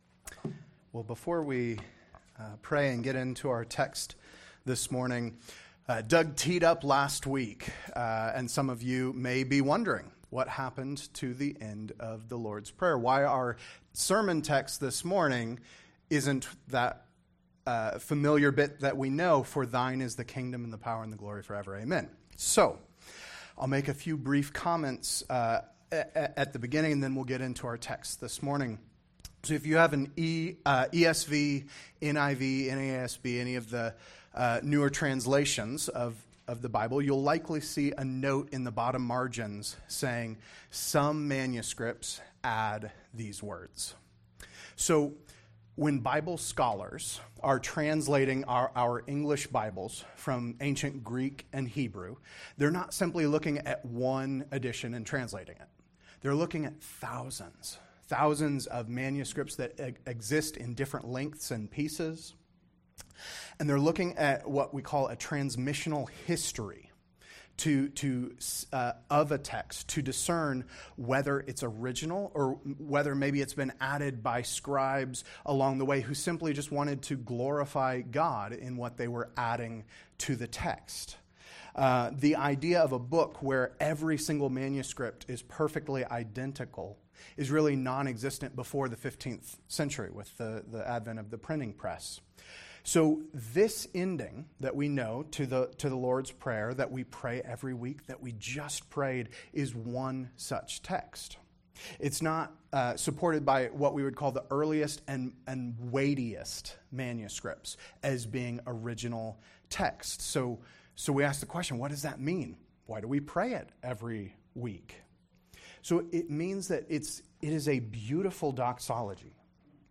Sermon text: Matthew 6:14-15